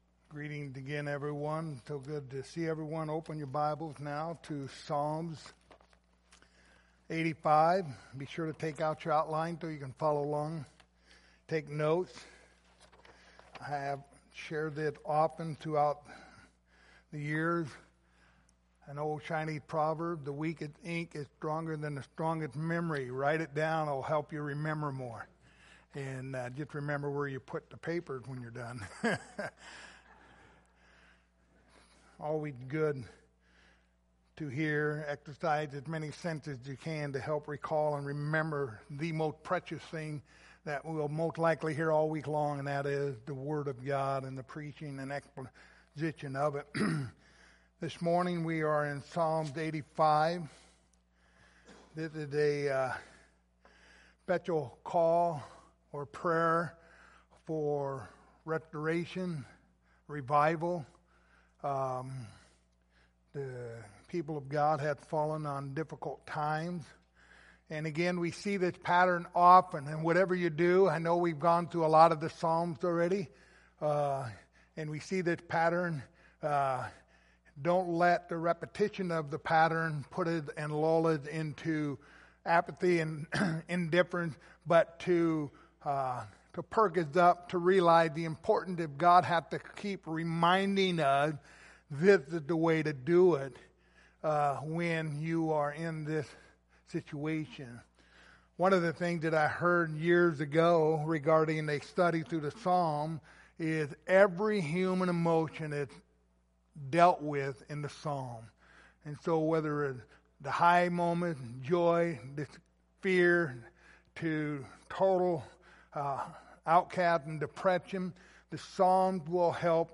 Passage: Psalms 85:1-13 Service Type: Sunday Morning Topics